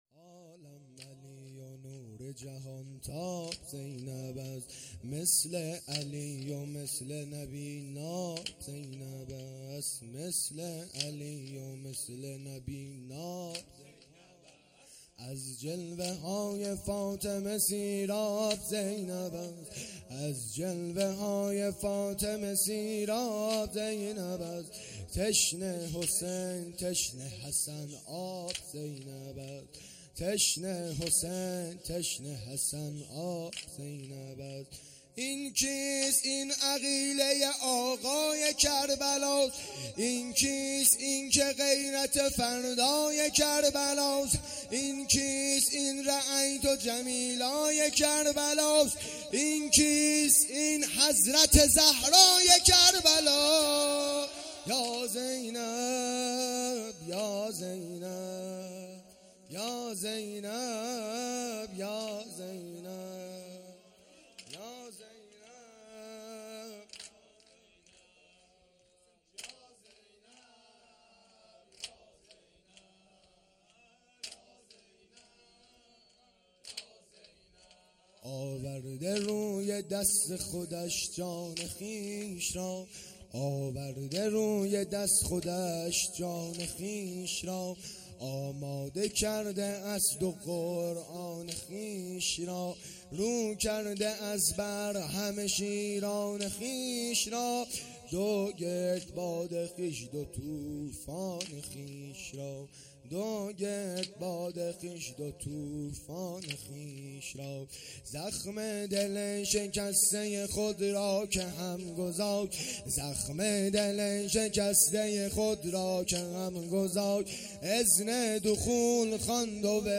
شب چهارم محرم الحرام ۱۴۴۳